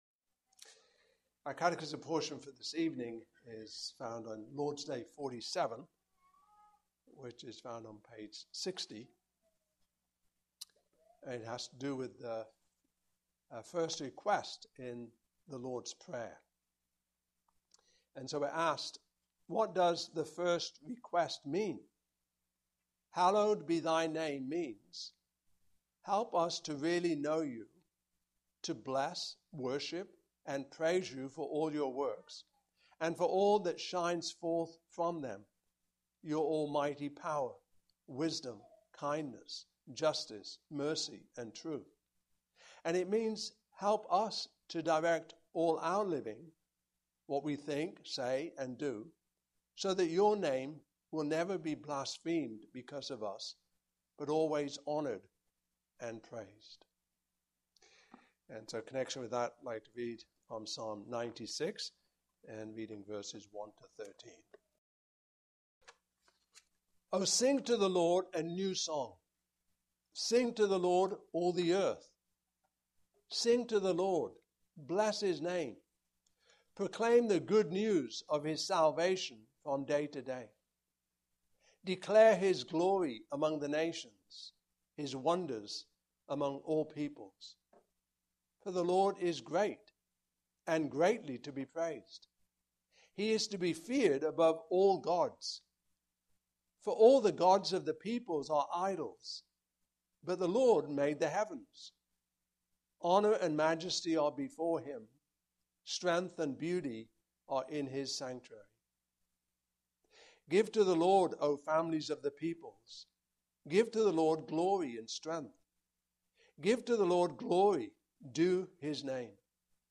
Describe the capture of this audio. Service Type: Evening Service Topics: Lord's Day #47 , Q.122